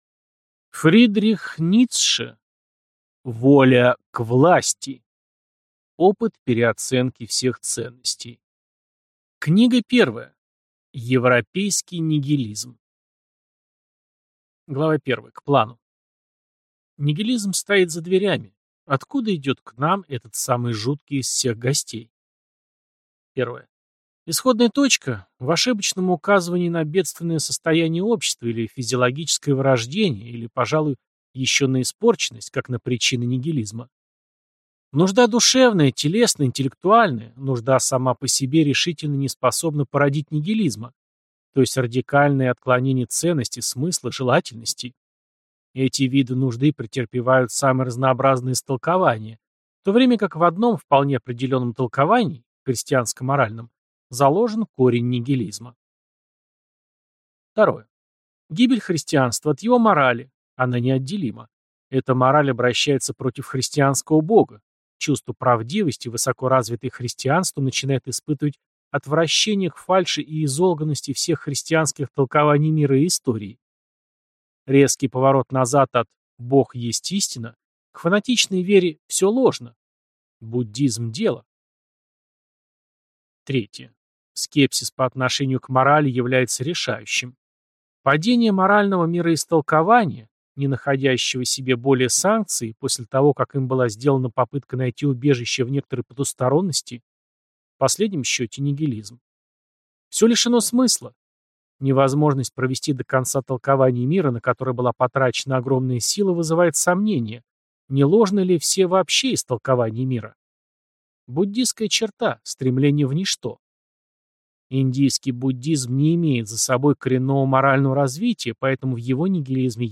Аудиокнига Воля к власти | Библиотека аудиокниг